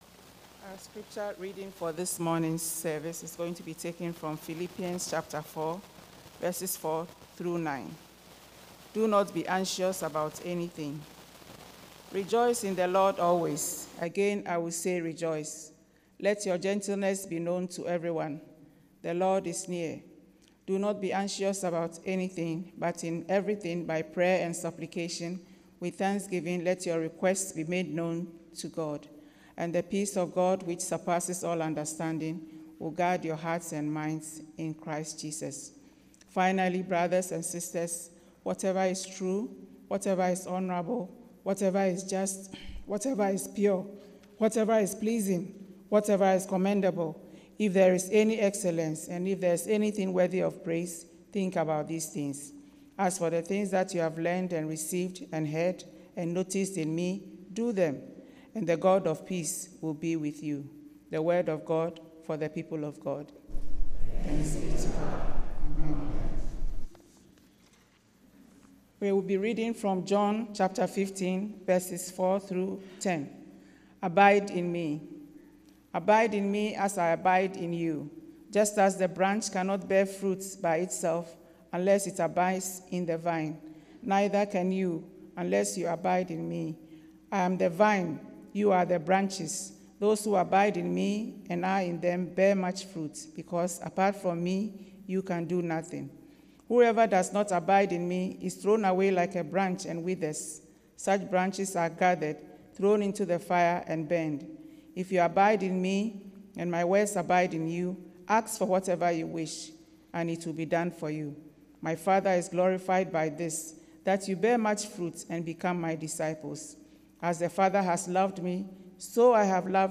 Sermon – Methodist Church Riverside
Thanksgiving Sunday sermon